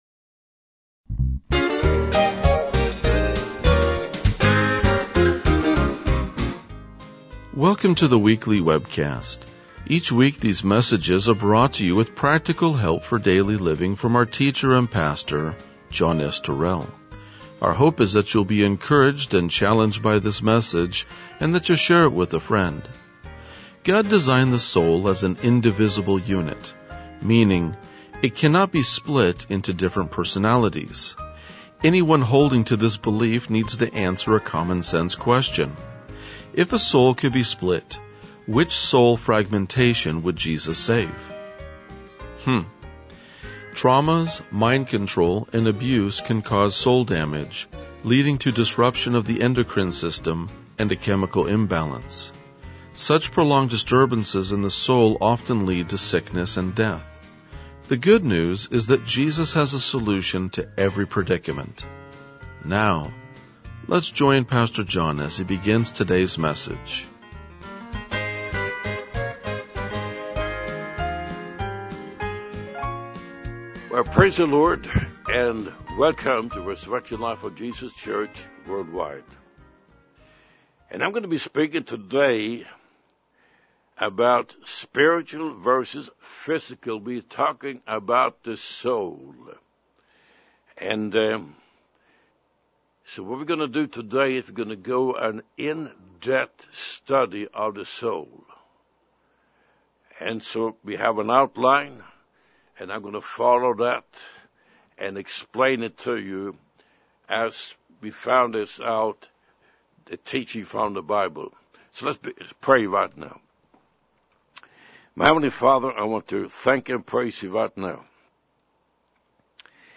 RLJ-2020-Sermon.mp3